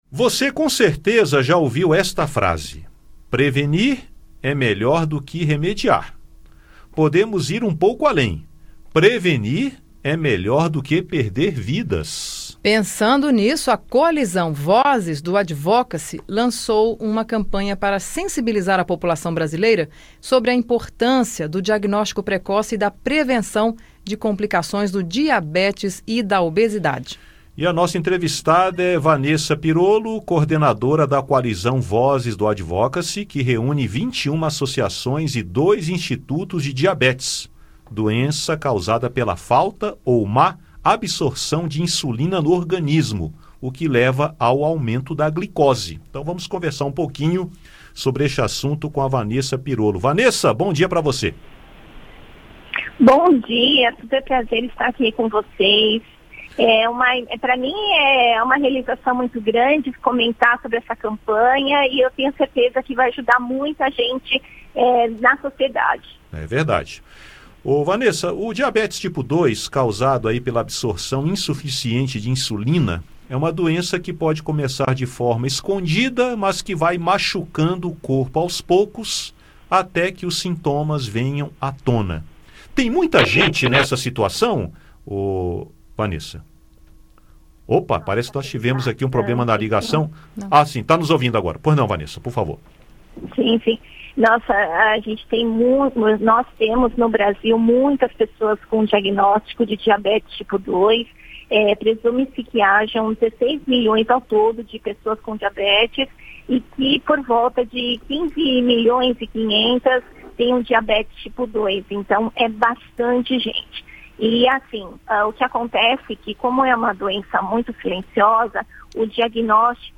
A coalizão Vozes do Advocacy, associação que reúne 21 entidades e 2 institutos de estudos e apoio a diabéticos, lançou uma campanha para sensibilizar a população brasileira sobre a importância do diagnóstico precoce e da prevenção de complicações do diabetes e da obesidade. Em entrevista ao Conexão Senado